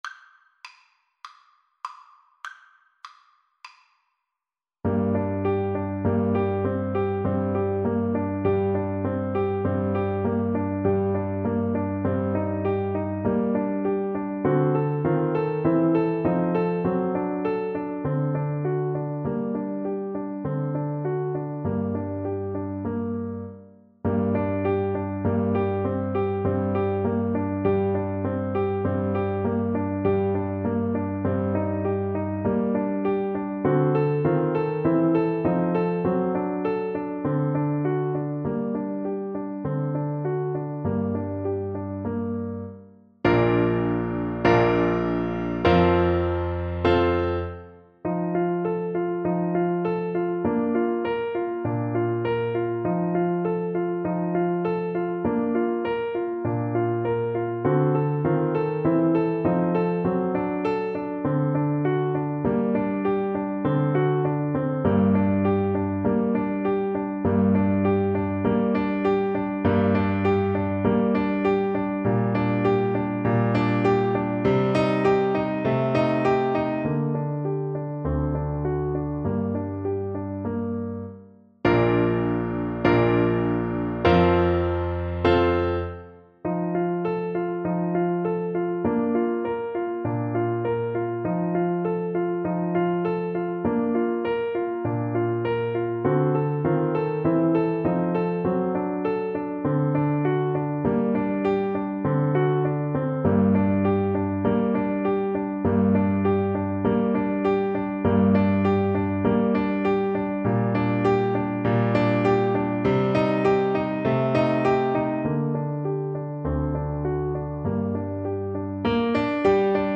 Play (or use space bar on your keyboard) Pause Music Playalong - Piano Accompaniment Playalong Band Accompaniment not yet available transpose reset tempo print settings full screen
Soprano Voice
G minor (Sounding Pitch) (View more G minor Music for Soprano Voice )
Andantino (View more music marked Andantino)
4/4 (View more 4/4 Music)
Classical (View more Classical Soprano Voice Music)